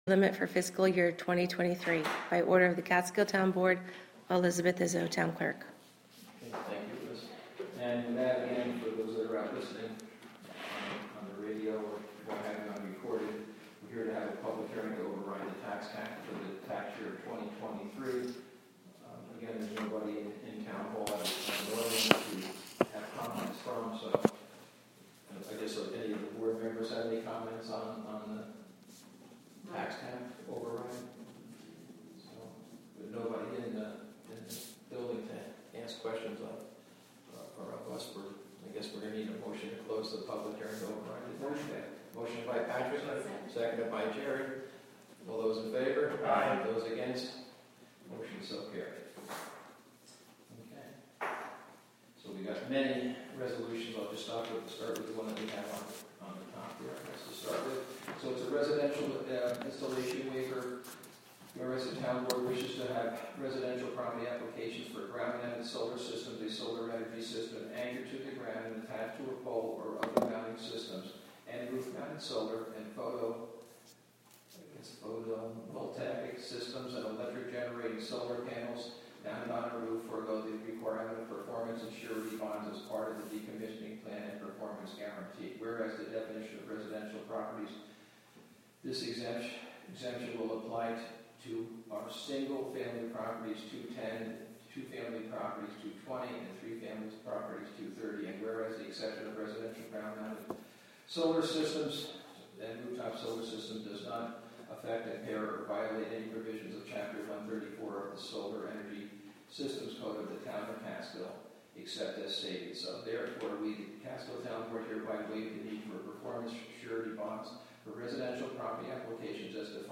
Live from the Town of Catskill: October 19; 2022 Town Board Meeting (Audio)